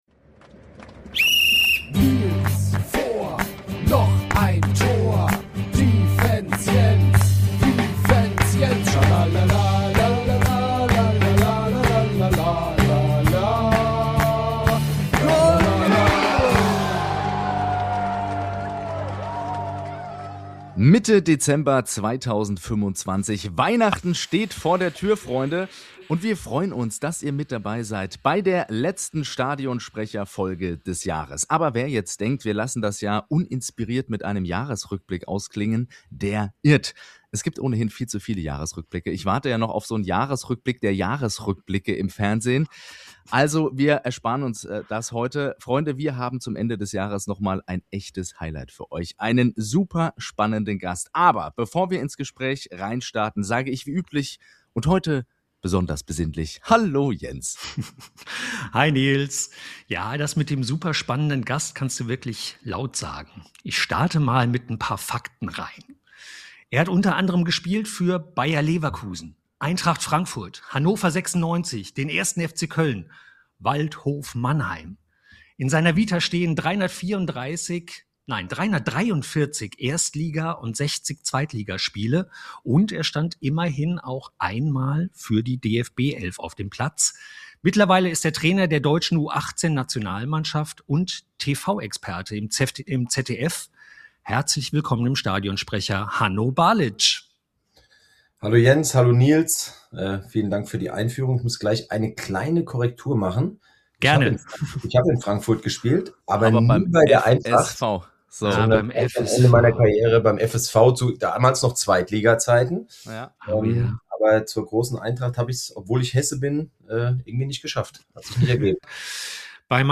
Ep.70 Ex-Profi, U18-Nationaltrainer, TV-Experte: Hanno Balitsch im Gespräch ~ STADIONSPRECHER - Der Podcast über Kommunikation im Fußball Podcast